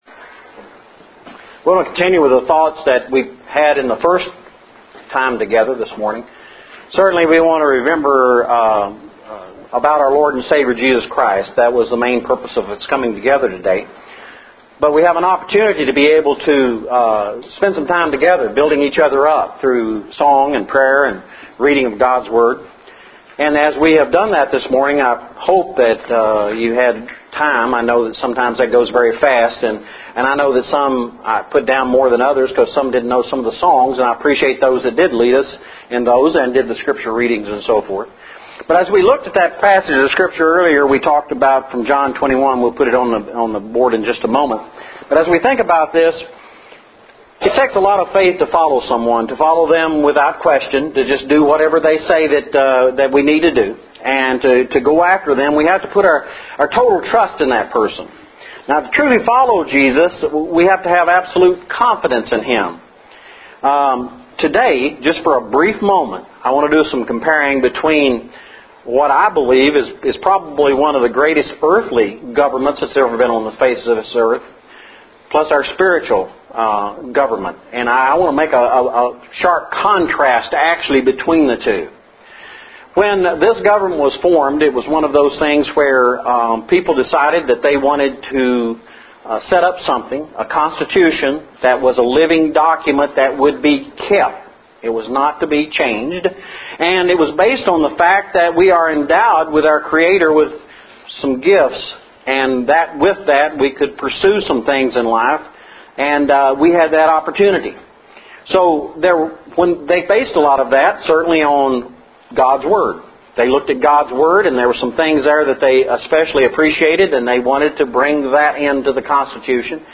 Our 5th Sunday lesson for September was entitled “Follow Jesus”.